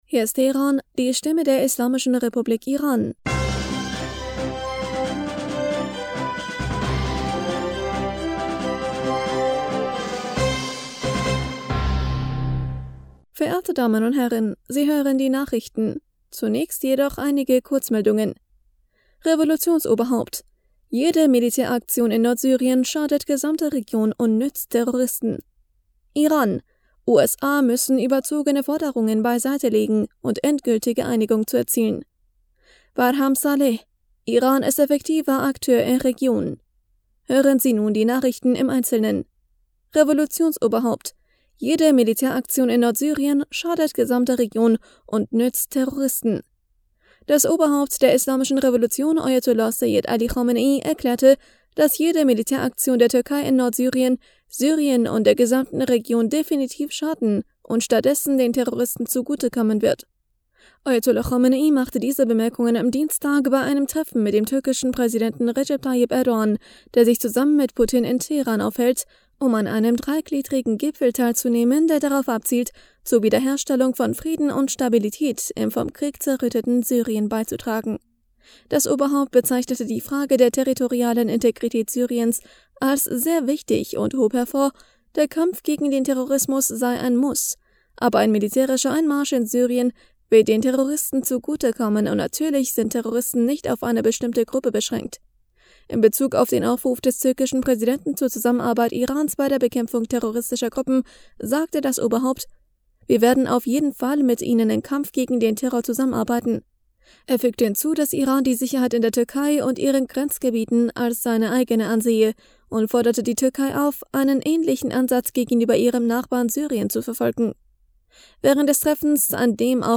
Nachrichten vom 19. Juli 2022